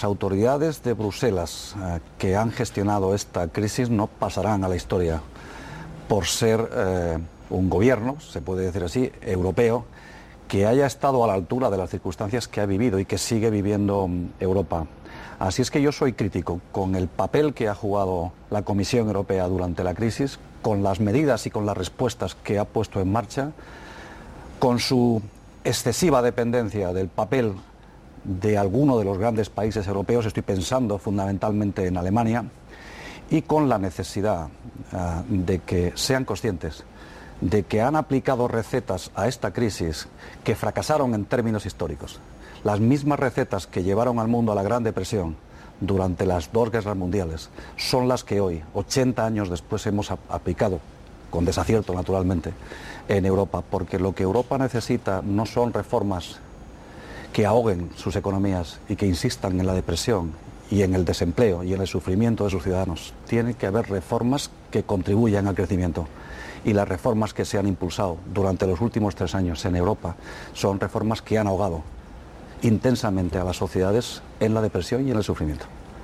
Vaeriano Gómez en los Desayunos de TVE